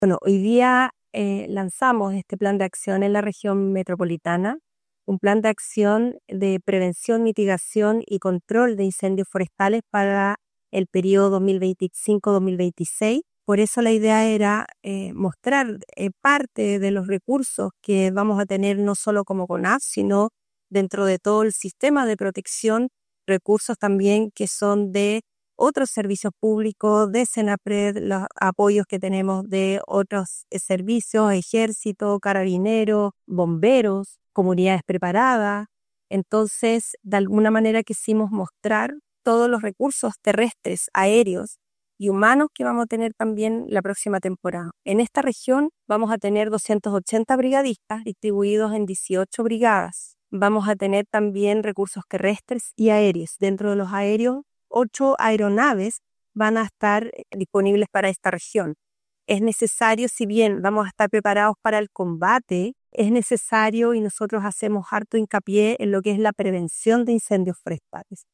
En el aeródromo de Curacaví se llevó a cabo el lanzamiento del Plan Regional de Acción de Prevención, Mitigación y Control de Incendios Forestales 2025-2026, actividad que contó con la presencia del delegado presidencial provincial de Melipilla, Bastián Alarcón; el director ejecutivo de CONAF, Rodrigo Illesca; la directora regional de CONAF Metropolitana, Elke Huss; y el director regional de SENAPRED, Miguel Muñoz, participando diversas autoridades, entre otros servicios públicos, privados y voluntariados que son parte de la emergencia.